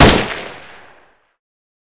8abddf23c7 Divergent / mods / Boomsticks and Sharpsticks / gamedata / sounds / weapons / ak12custom / shoot.ogg 44 KiB (Stored with Git LFS) Raw History
shoot.ogg